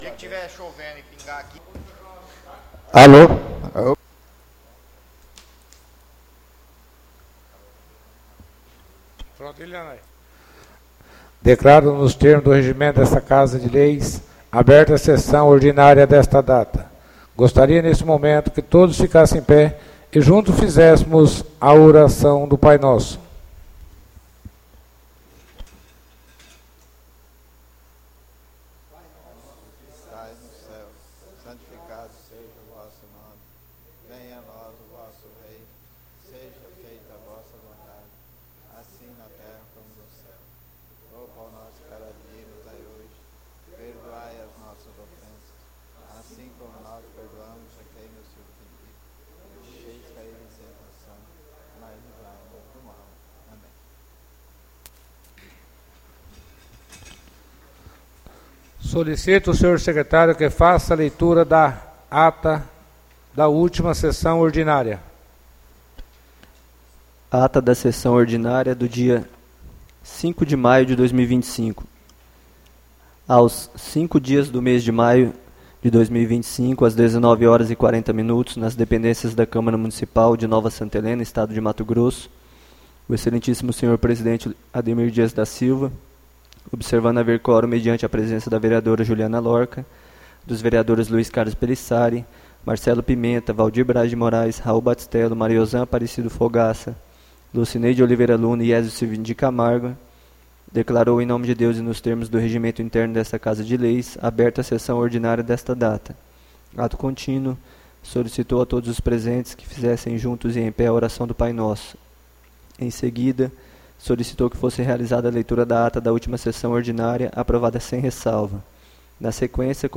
ÁUDIO SESSÃO 12-05-25 — CÂMARA MUNICIPAL DE NOVA SANTA HELENA - MT